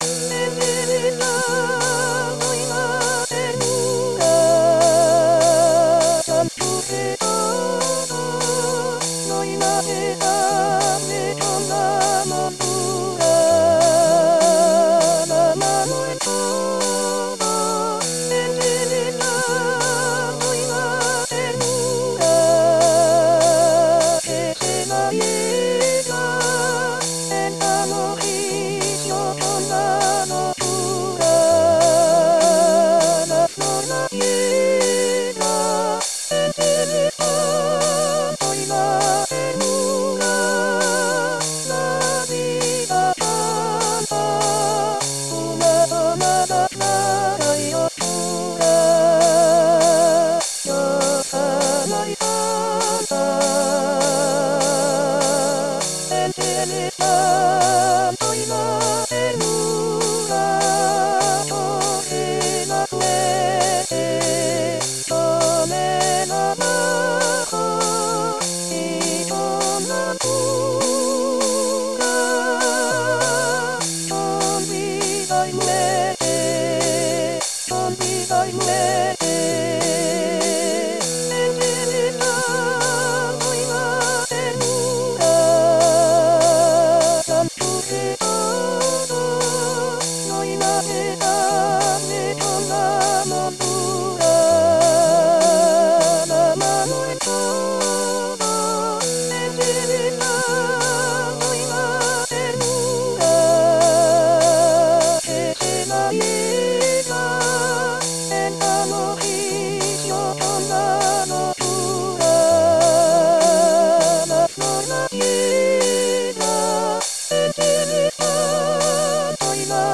Soprano Soprano 1